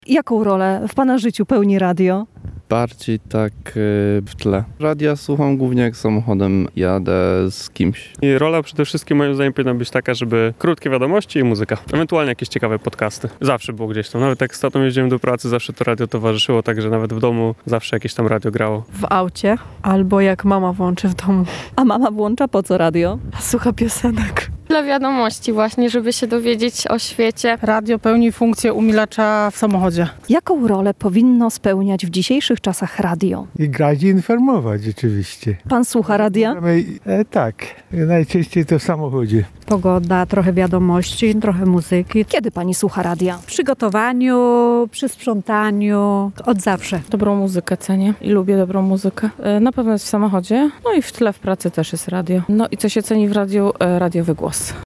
Co cenimy w radiu i gdzie najczęściej go słuchamy? (sonda)
Dzien-Radia-sonda.mp3